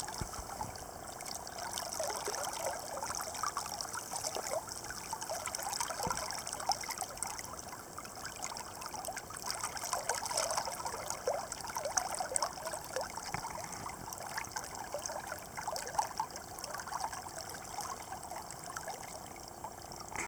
実は川の流れる音をかなり気にしてます、正しくは流速音なのかな。これは本流のダブルハンドでステップダウンする（釣り降る）時に聞こえてくる太ももに受ける音なのですが、ある一定の音色が聞こえてくると急に身が引き締まってステップダウンの速度が落として丁寧に釣りをします。
どんな音と言われても難しいので釣りをしながら音を録ってきましたが、耳で聞く音とちょっと違う感じ。甲高く、湿っぽく、そしてリズムミカルに音域が心地よく上下する。私的にはゲゲゲの鬼太郎の「♪から〜ん、ころ〜ん、カランカラン、コロン♪」と言うリズムのピッチを早くした感じに聞こえます。